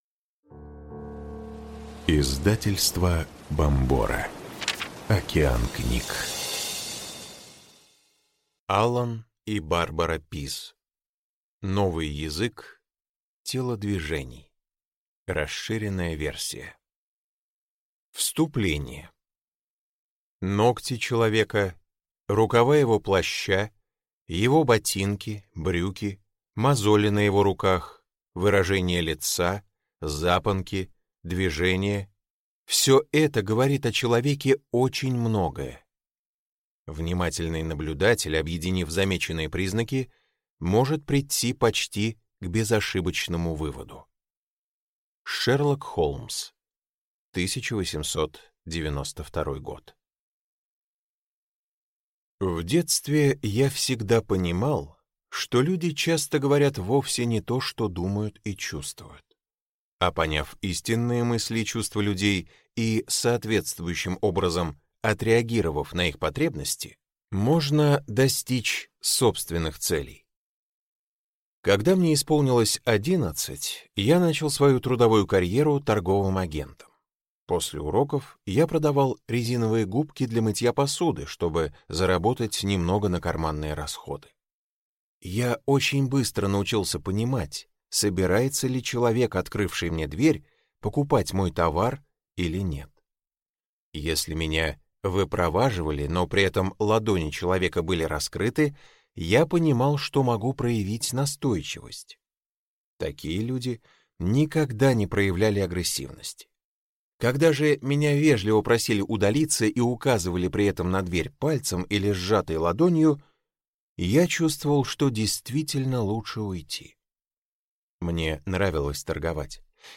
Аудиокнига Новый язык телодвижений. Расширенная версия | Библиотека аудиокниг